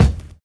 VINYL 8 BD.wav